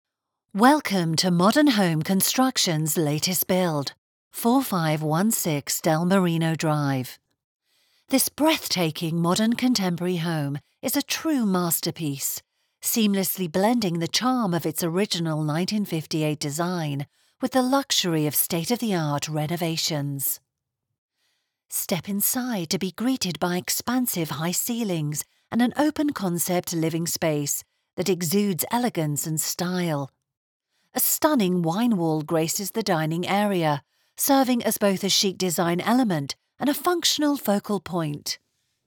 With my clear, bright British tones, I bring a touch of class to every project, ensuring your message resonates with listeners everywhere.
Words that describe my voice are British, Friendly, Natural.